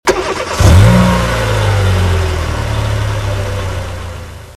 Home gmod sound vehicles tdmcars gullwing
enginestart.mp3